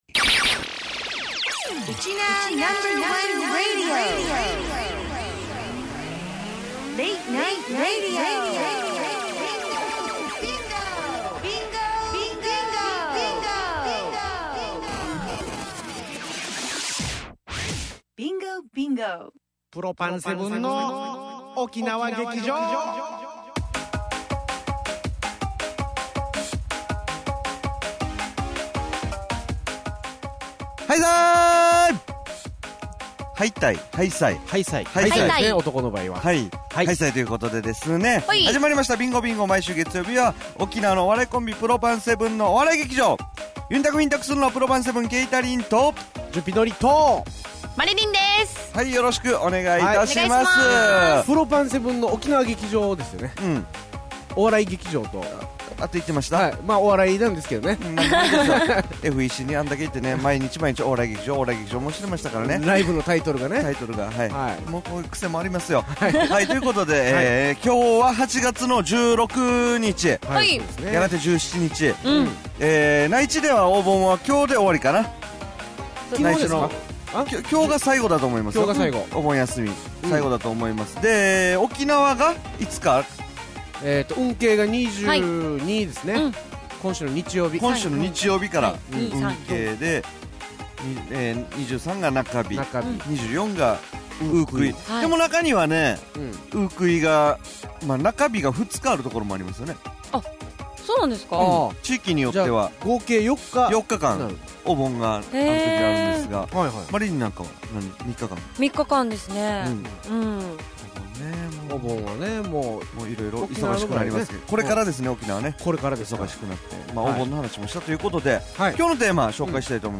毎週月曜２３時から１時間 生放送。